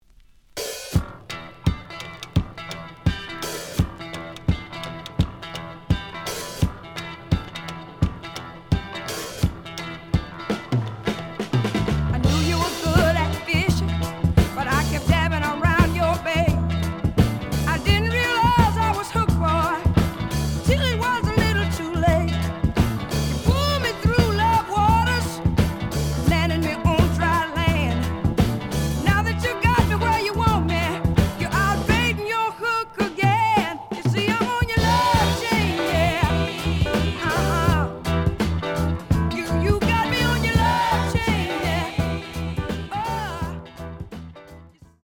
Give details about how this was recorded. The audio sample is recorded from the actual item. Slight cloudy on both sides. Plays good.)